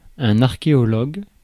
Ääntäminen
Synonyymit archéologiste Ääntäminen Tuntematon aksentti: IPA: /aʁ.ke.ɔ.lɔɡ/ Haettu sana löytyi näillä lähdekielillä: ranska Käännös 1. arkæolog {c} Suku: m .